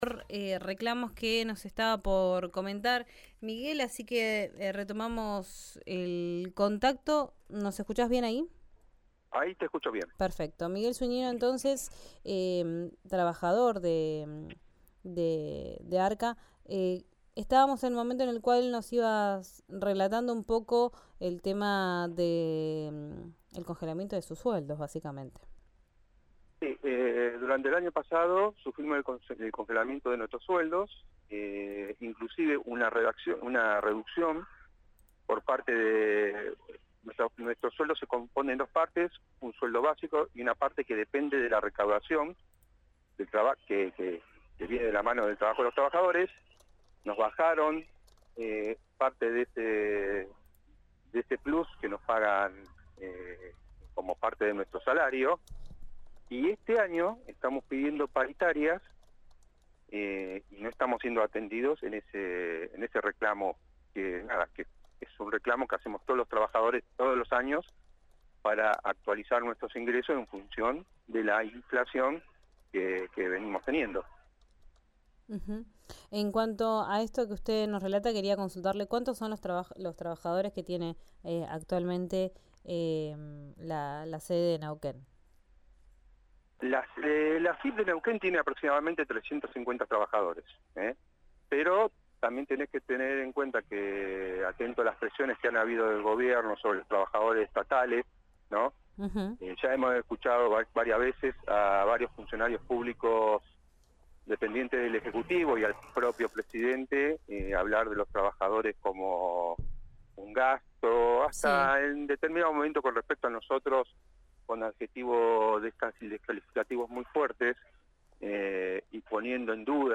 En una entrevista con RIO NEGRO RADIO